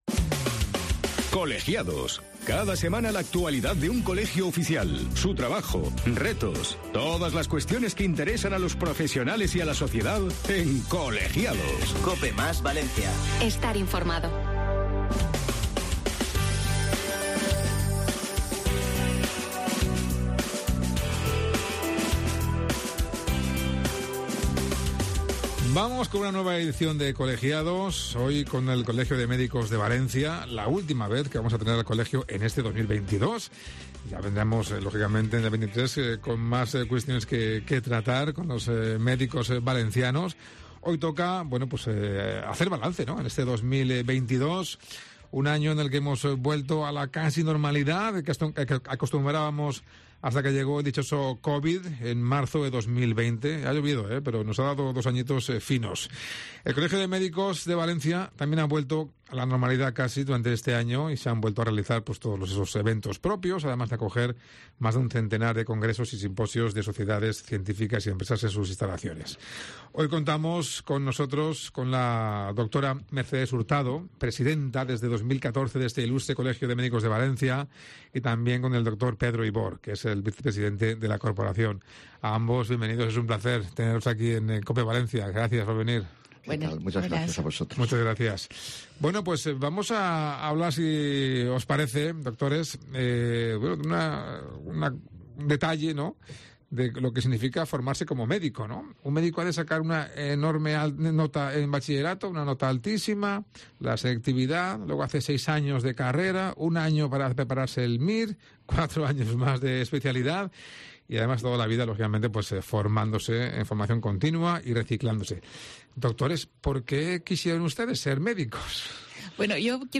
Los doctores han aprovechado su presencia ante nuestros micrófonos para analizar la situación actual del colectivo médico ante la alta incidencia de las enfermedades infecciosas de caracter respiratorio como gripe y resfriados, el aumento de las listas de espera, los colapsos en urgencias; mientras se reclama la contratación de personal para solucionar estos problemas.